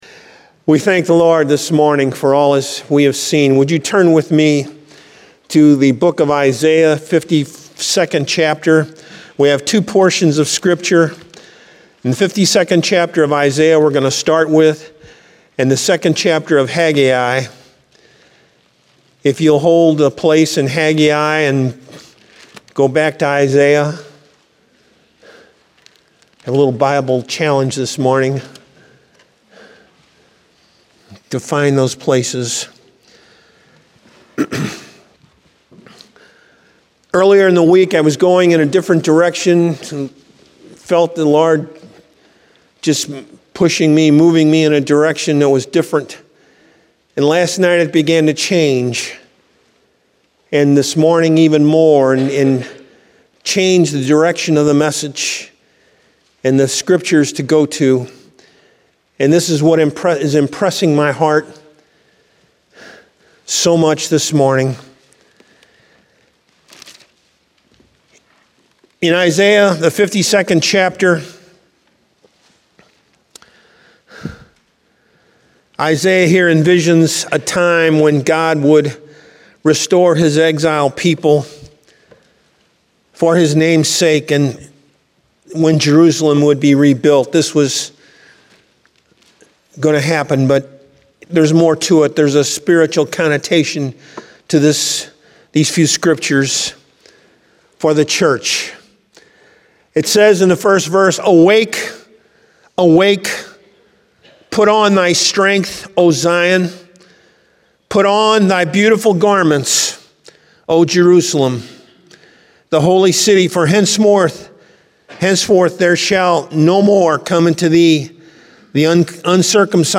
Download Sermon Audio File Evangelical Full Gospel Assembly